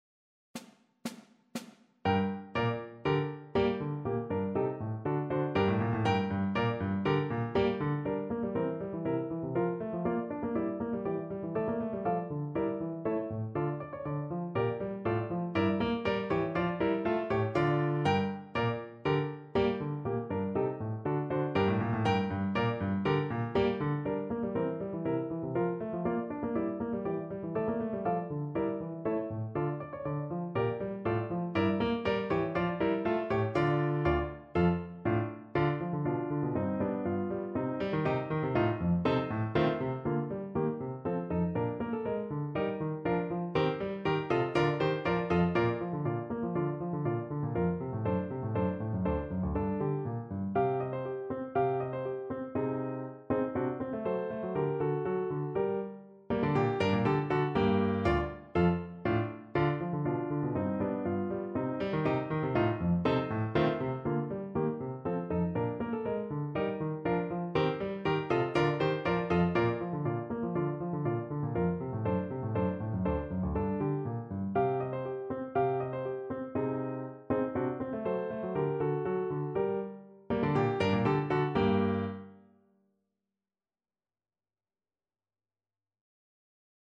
Bach: Badinerie (na klarnet i fortepian)
Symulacja akompaniamentu